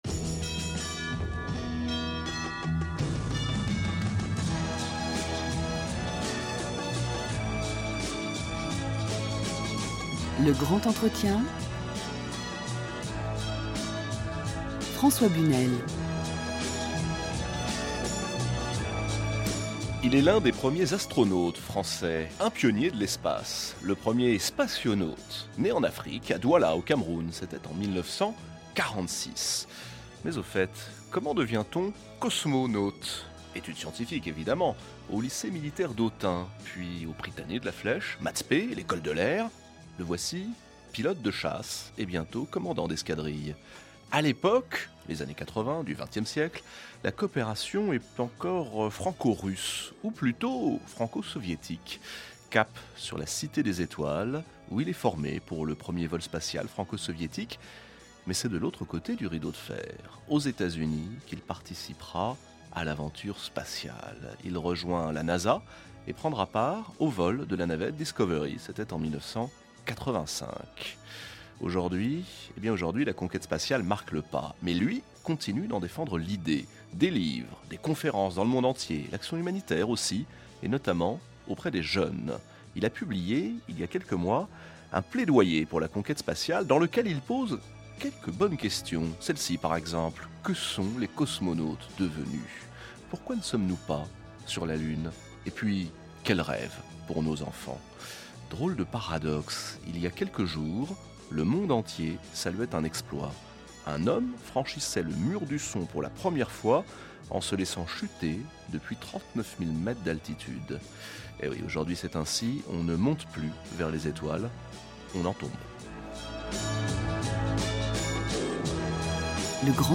Retrouver l’émission de France Inter avec l’interview de Patrick Baudry (Le grand entretien).
lge-2012-Patrick-Baudry-France-Inter.mp3